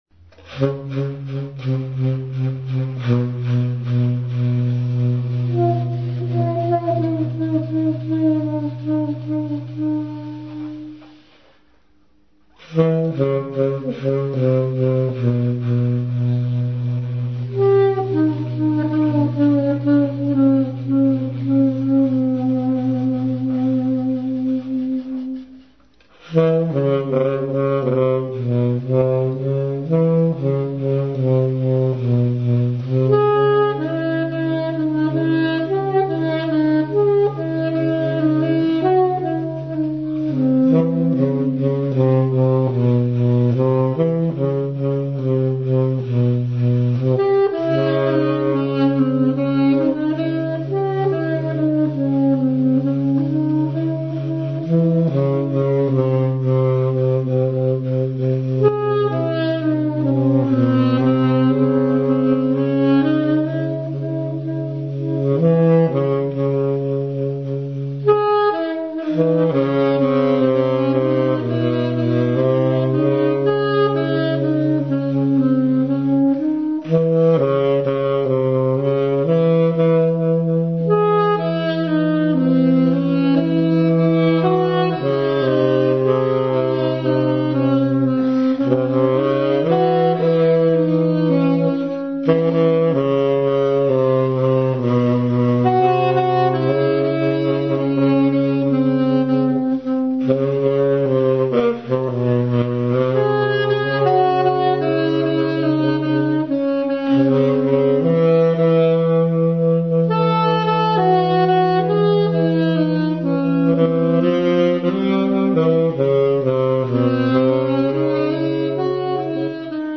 alto saxophone, piano, percussion
tenor and soprano saxophone, percussion
Szoboszló Gallery, Hajdúszoboszló